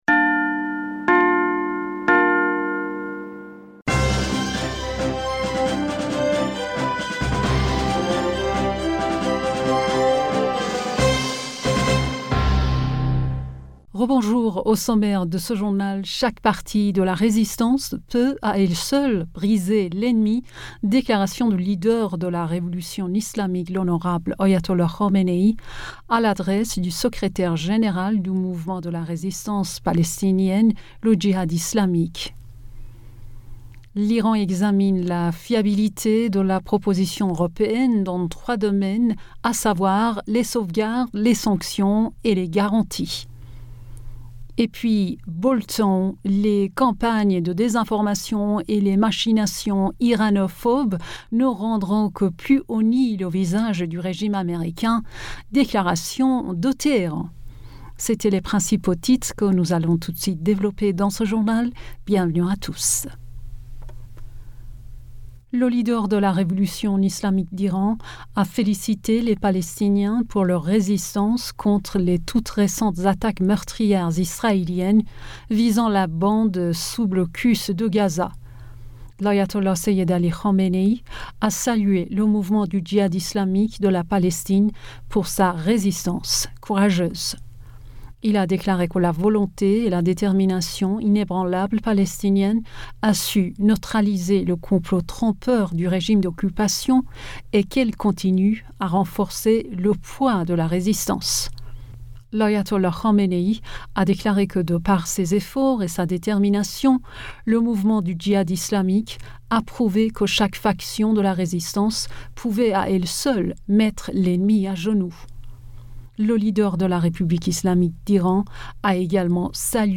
Bulletin d'information Du 12 Aoùt